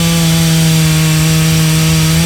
sfx_chainsaw_active_1.wav